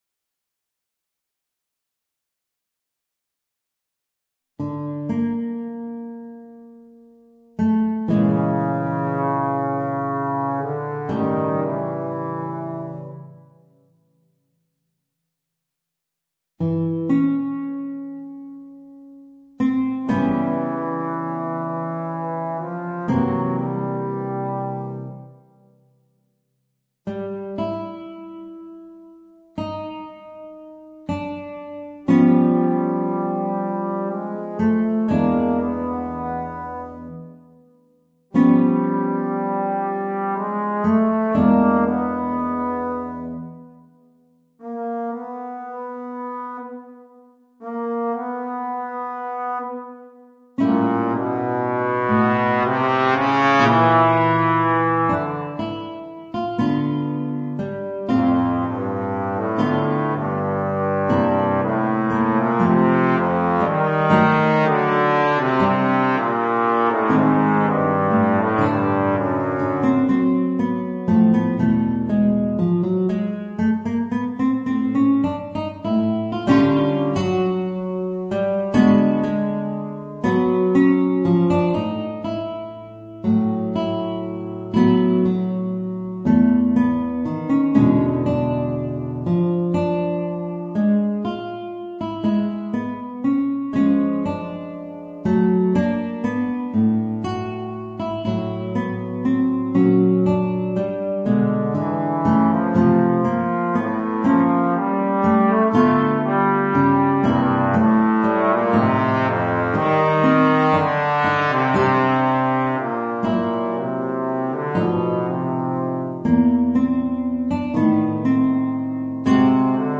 Voicing: Bass Trombone and Guitar